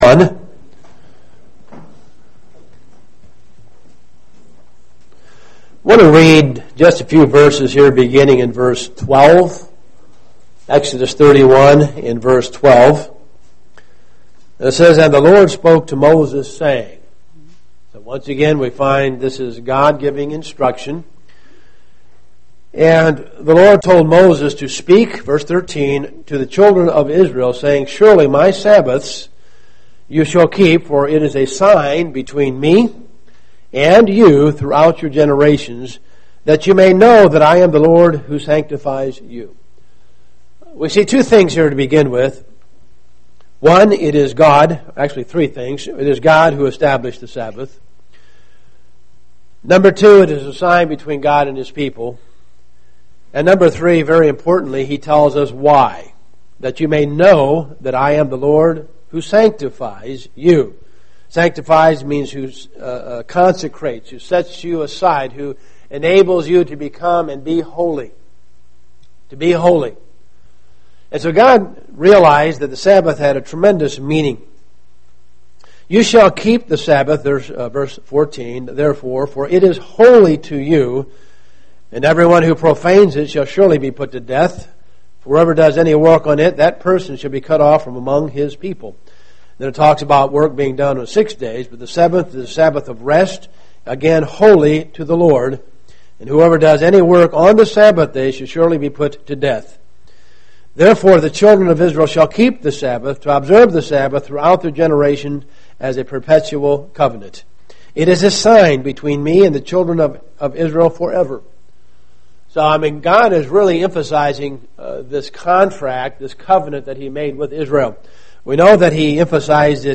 Print There are three positions society has on the Sabbath. 1)The Sabbath is done away with 2)The Sabbath was changed 3)The Sabbath should be kept UCG Sermon Studying the bible?